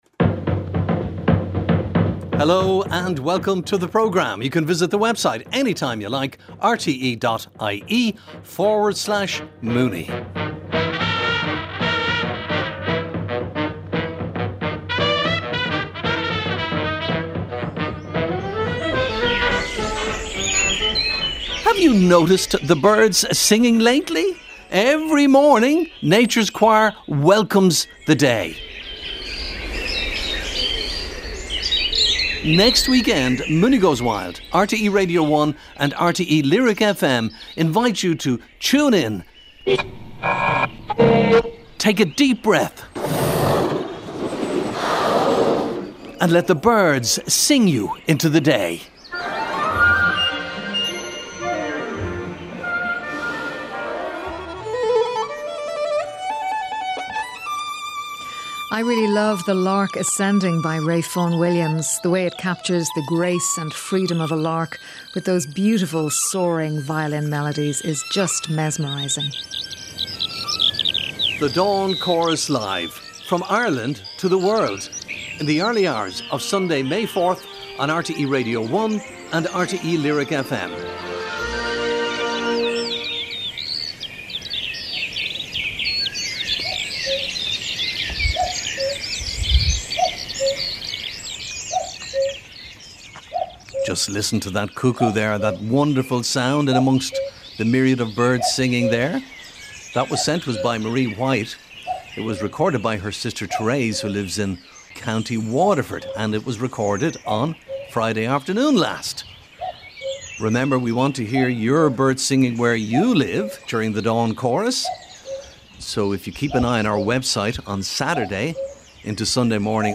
Dawn Chorus sounds preview.
First up on tonight’s programme, our panel looks forward to one of the biggest and most anticipated natural history broadcasting events of the year: our annual Dawn Chorus Live programme.